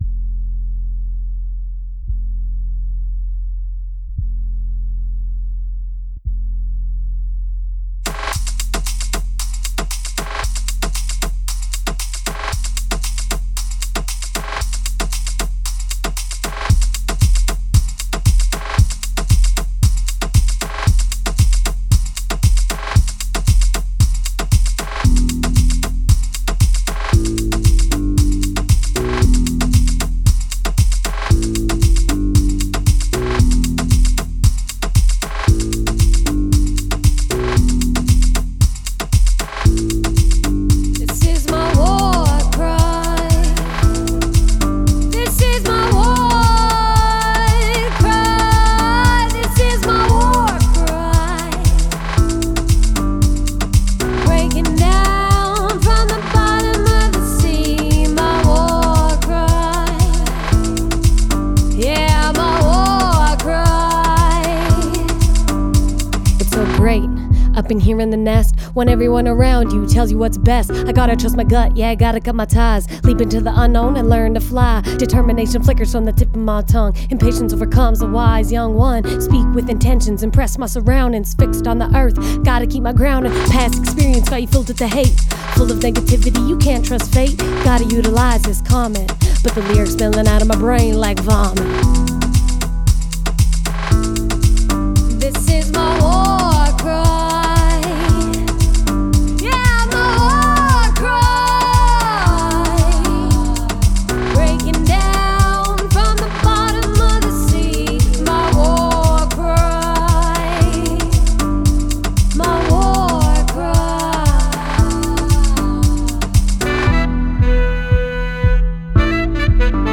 28 min of Western Canadian indie music mix